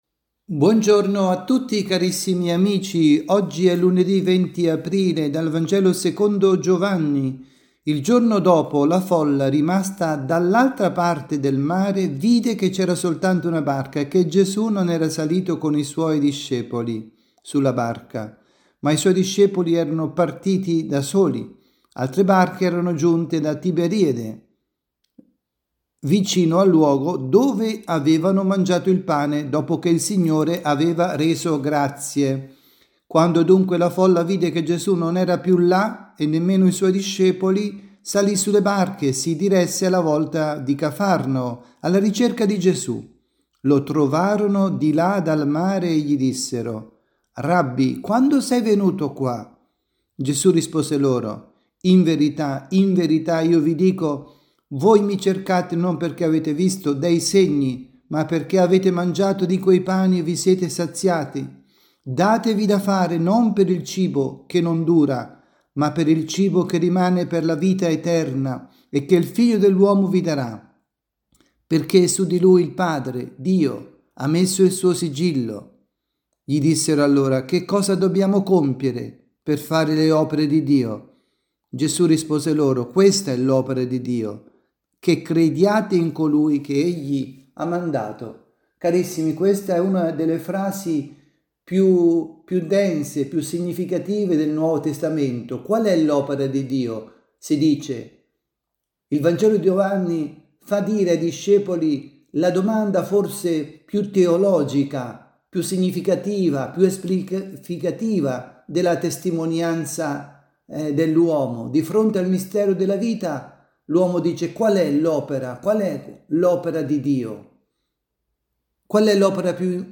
avvisi, Catechesi, Omelie, Pasqua